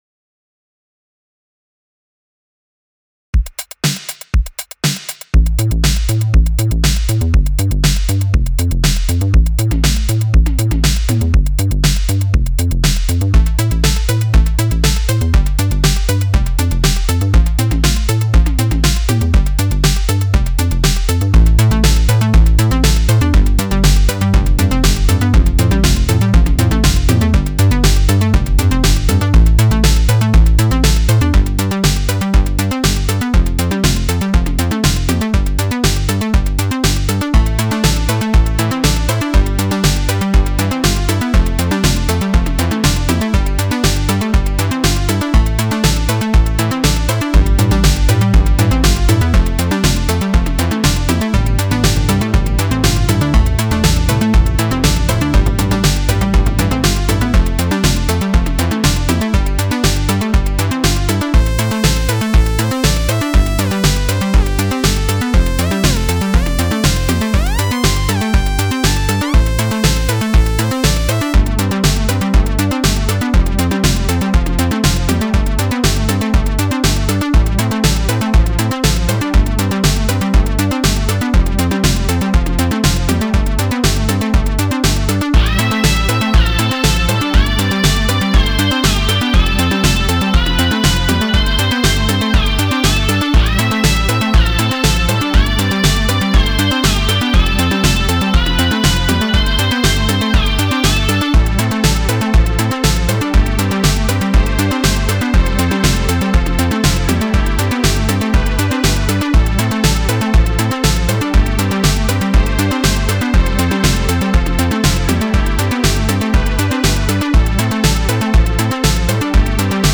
Eurodance
Автор музыки я,аранжировки я и eurosynth pad